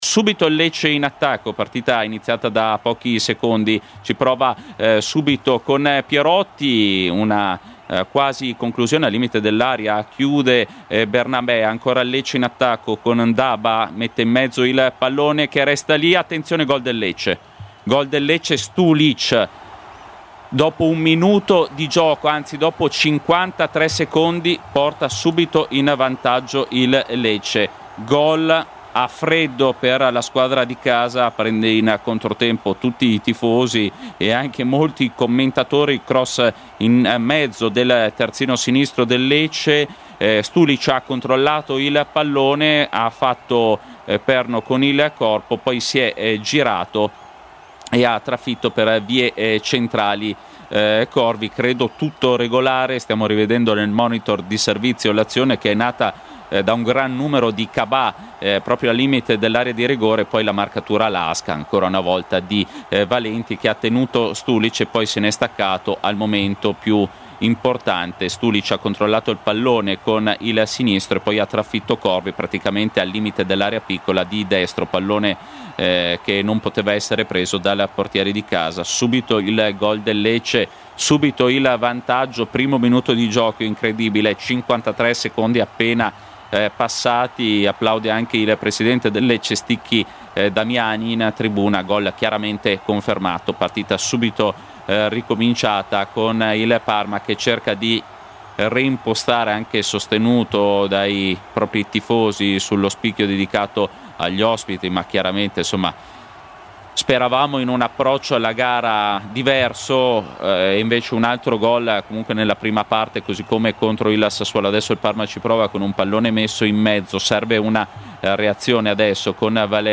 Radiocronache Parma Calcio Lecce - Parma 1° tempo - 11 gennaio 2026 Jan 11 2026 | 00:45:49 1x 00:00 / 00:45:49 Subscribe Share RSS Feed Share Link Embed